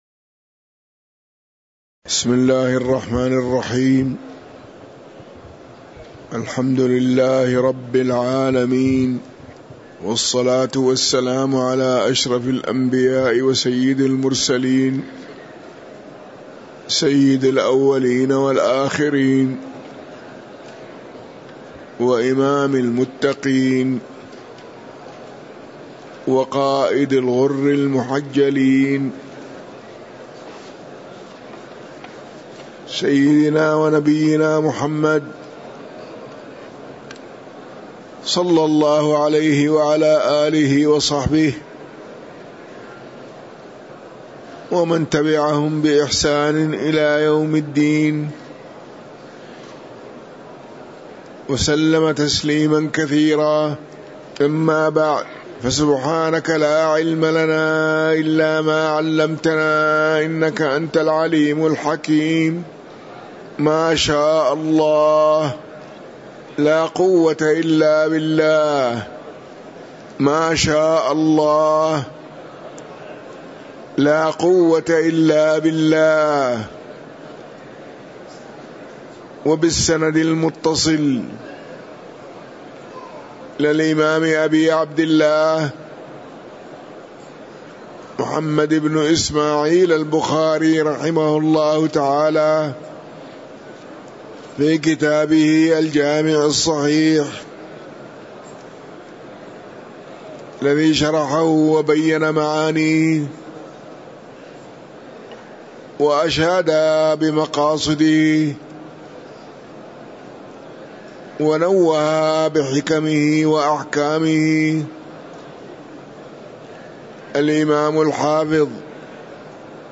تاريخ النشر ١٩ شوال ١٤٤٤ هـ المكان: المسجد النبوي الشيخ